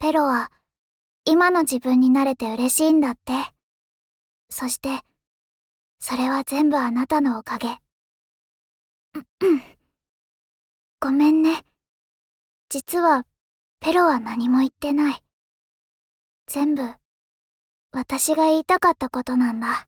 ロココはボイス聞いて手の平くるーしたけど🥺🤚
play_favor_word_luokeke_sys_rankup05.mp3